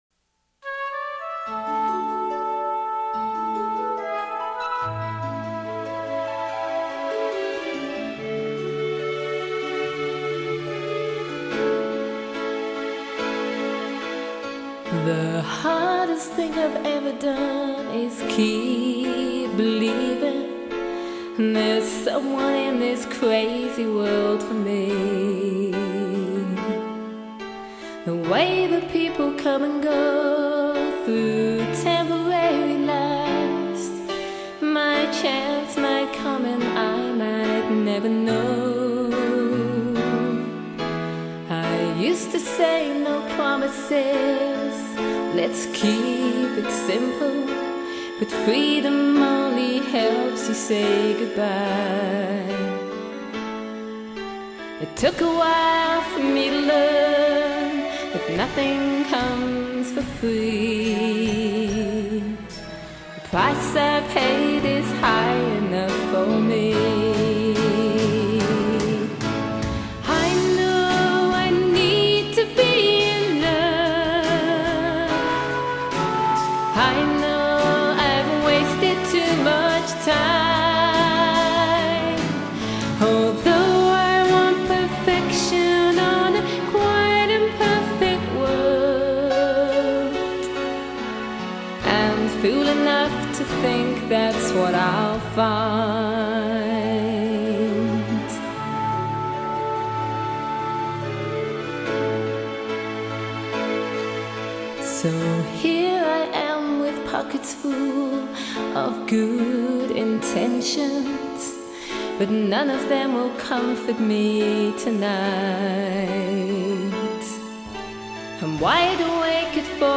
This is live music
keyboards